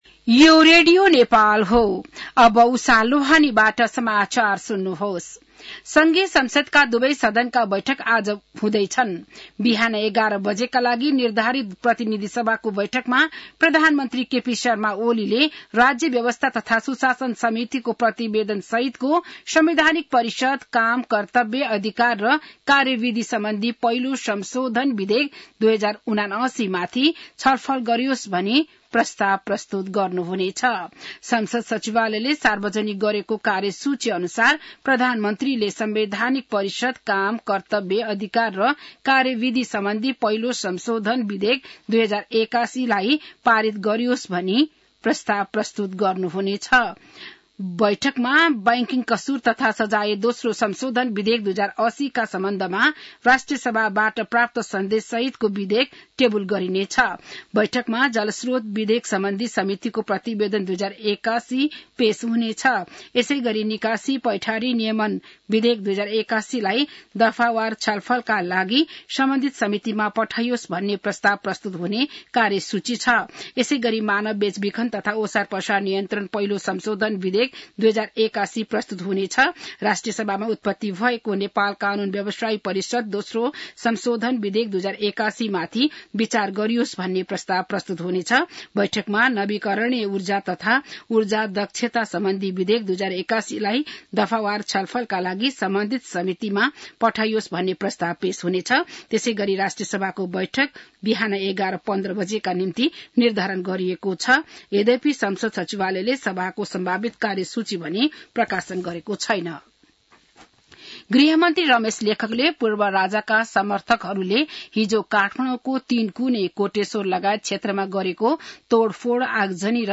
बिहान १० बजेको नेपाली समाचार : १७ चैत , २०८१